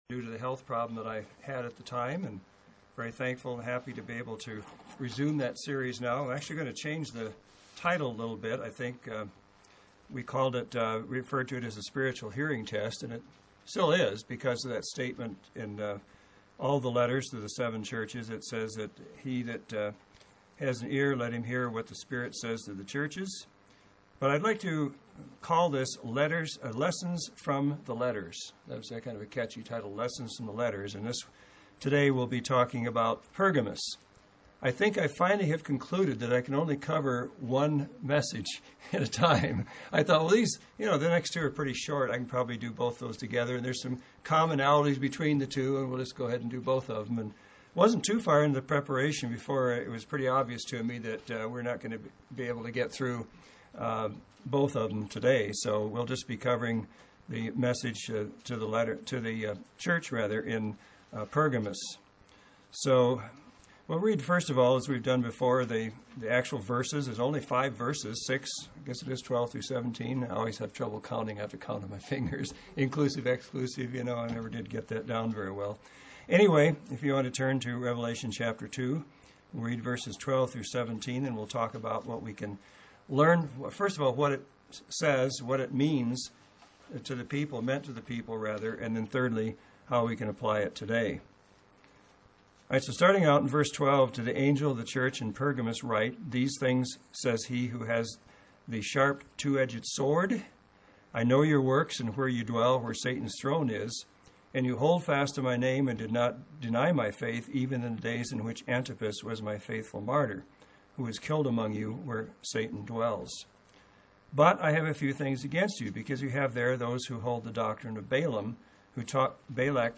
This means that these letters contain vital information relevant to all of us, even today. This Bible study series, which began November 6, 2010, analyzes what we can learn from each of these letters.